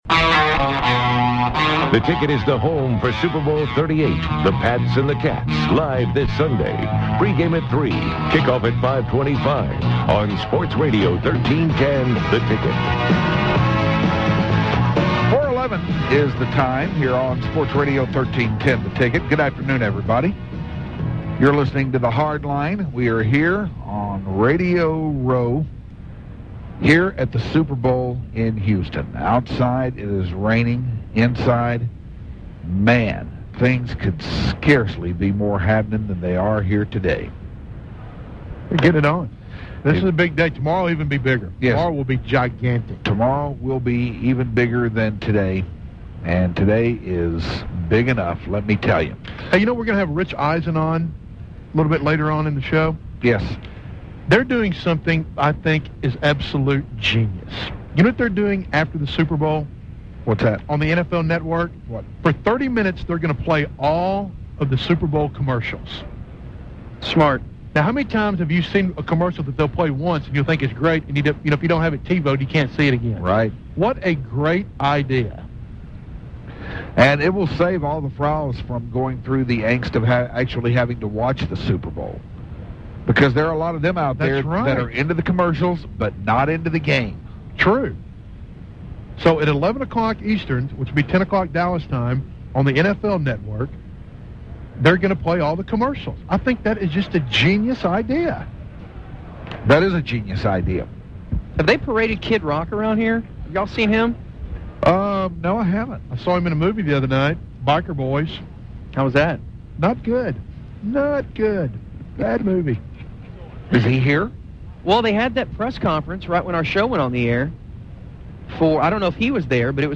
Yanni Interview 1-29-04